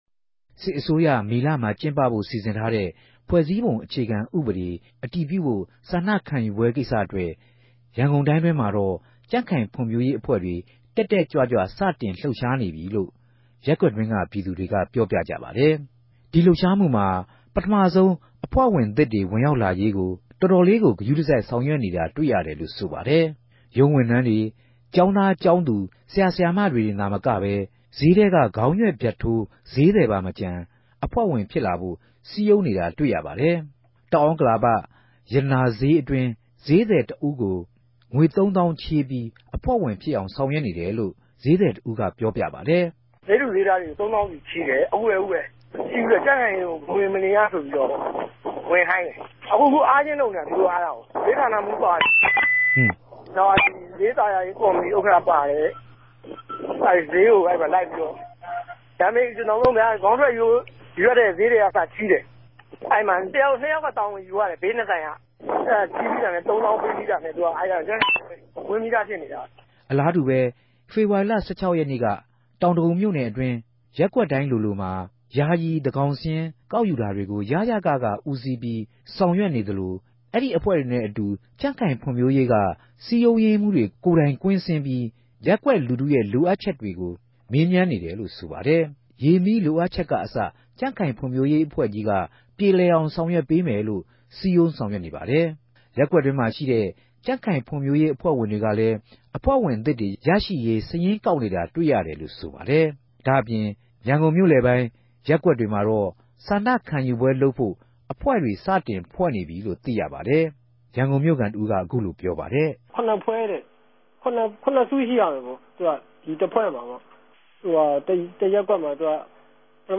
ရန်ကုန်္ဘမိြႚခံတဦး။ ။ သူတိုႛက ယာယီသန်းခေၝင်စာရင်းတြေ ကောက်တယ်၊ ကောက်္ဘပီးတော့မြ ကေဵာင်းလိုနေရာမဵိြး တခုမြာစုခေၞ္ဘပီး ေူခခံဥပဒေမူုကမ်းကို ထောက်ခံဖိုႛ မဲပေးခိုင်းမယ်ပေၝ့၊ မဲပေးတဲ့နေရာမြာ သူတိုႛကရြင်းူပပေးမယ်၊ ရြင်းူပပေး္ဘပီးရင် သူတိုႛကို ထောက်ခံတဲ့သူဆိုရင်နေခဲ့ မထောက်ခံတဲ့သူက ူပန်သြားိံိုင်တယ်လိုႛေူပာတယ်။